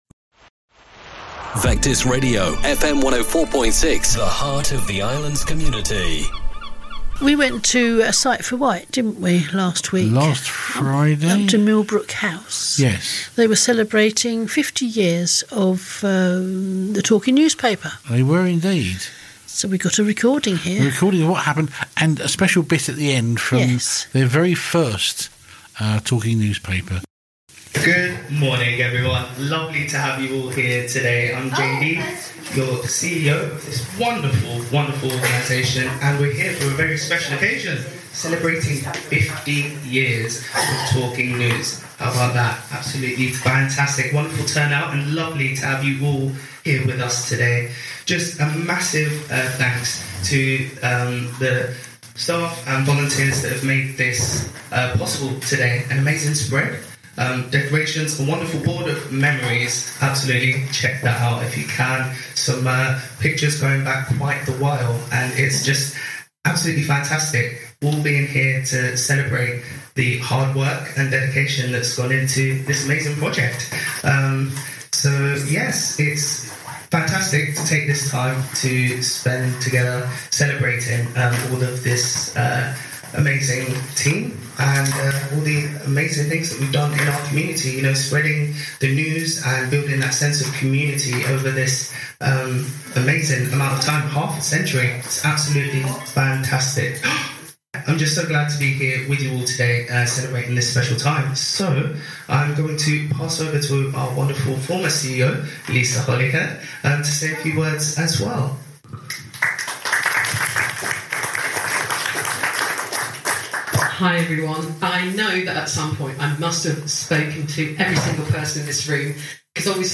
at the Sight for Wight 50 years of talking newspaper event